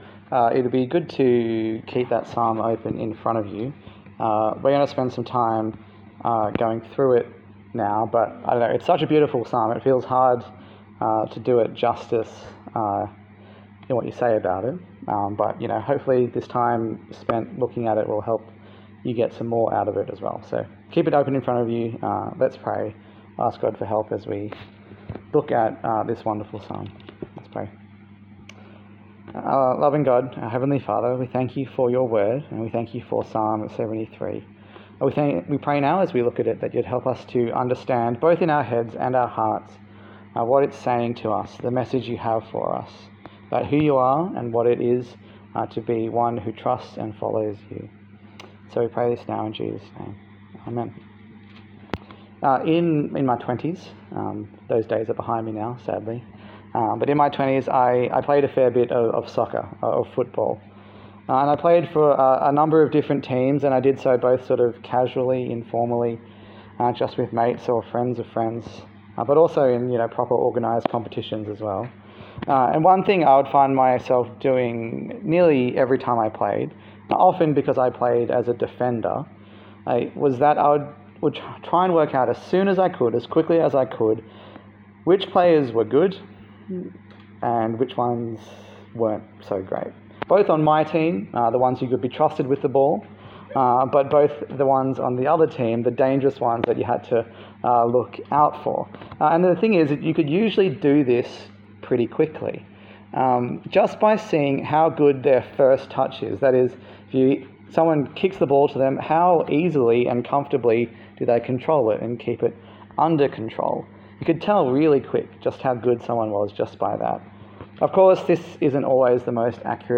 A sermon in the series 'Songs for Summer' featuring the book of Psalms.
Psalm 73 Service Type: Sunday Service A sermon in the series 'Songs for Summer' featuring the book of Psalms.